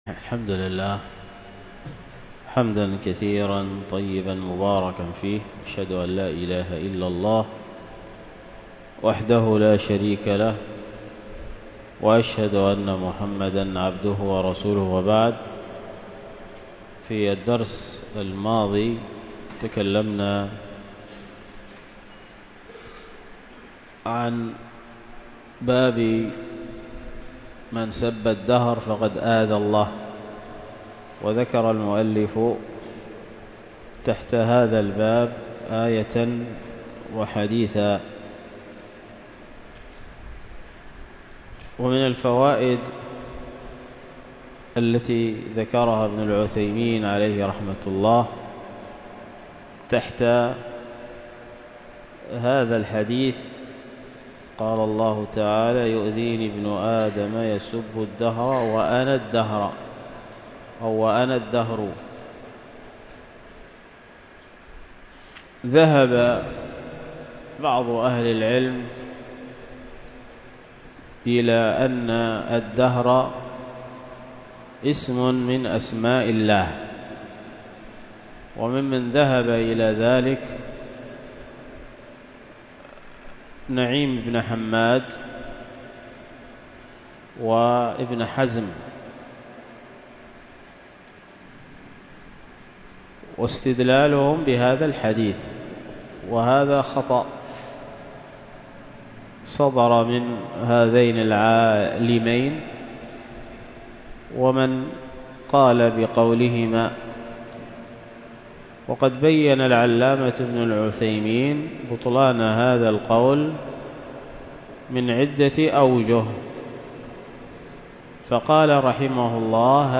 الدرس في شرح كتاب الصيام من فتح المعين في تقريب منهج السالكين 8